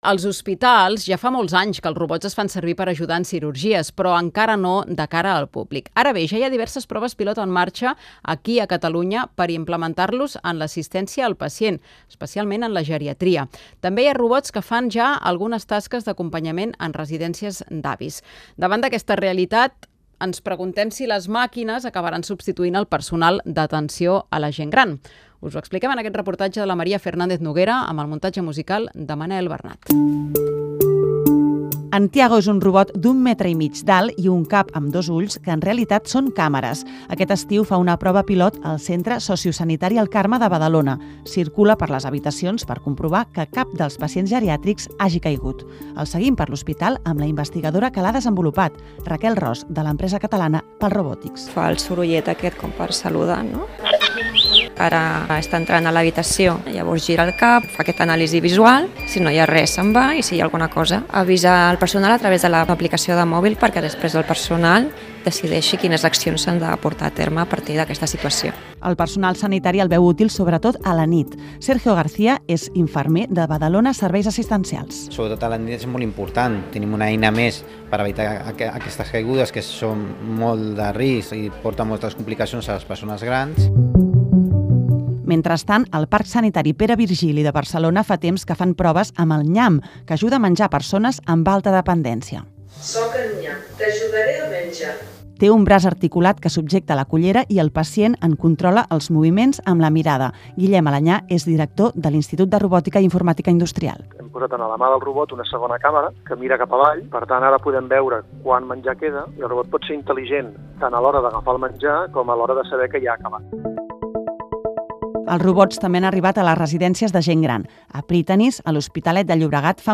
un reportatge emès per Catalunya Informació el 24 d'agost sobre robòtica als centes de salut i residències de gent gran.